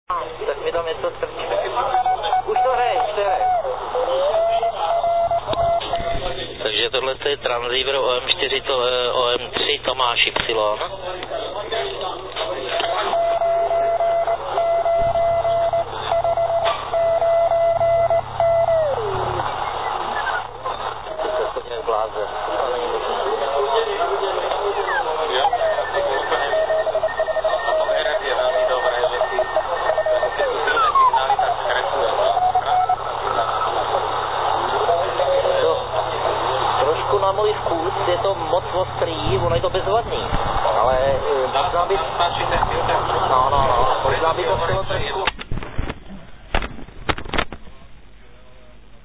Chodilo to skutečně skvěle.